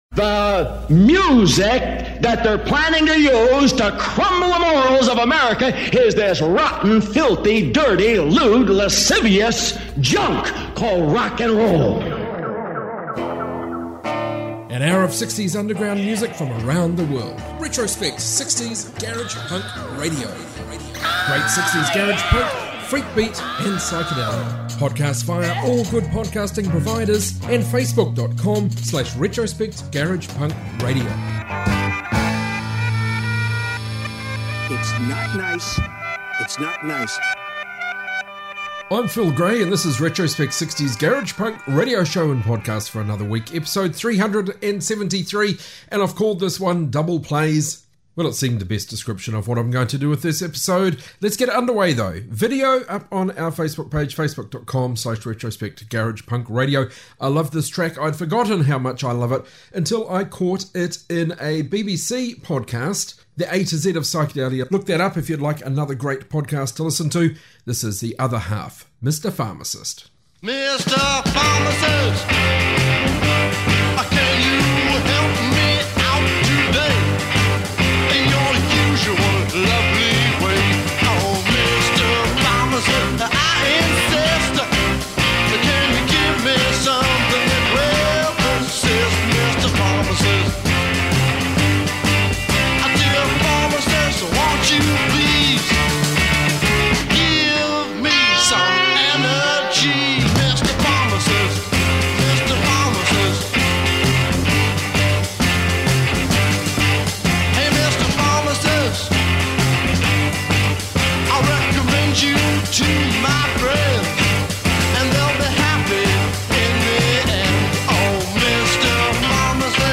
60s global garage rock